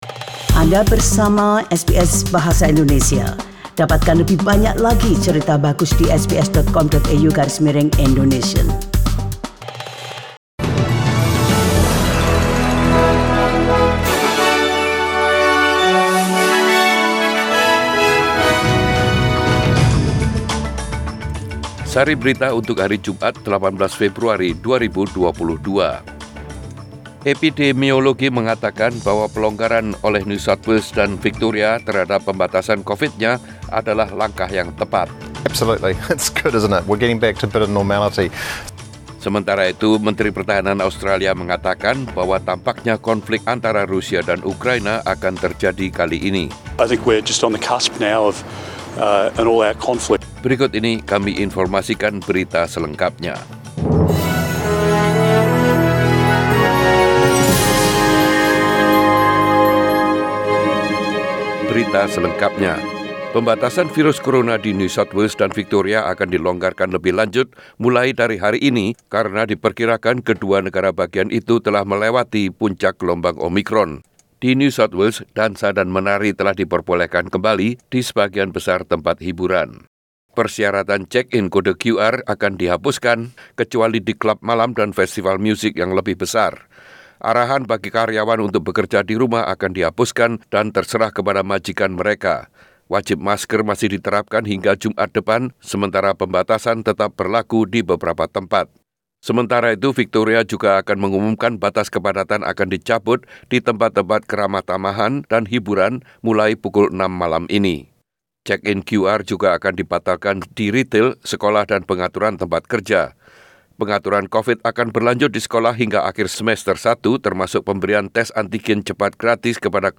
SBS Radio News in Bahasa Indonesia - 18 February 2022
Warta Berita Radio SBS Program Bahasa Indonesia.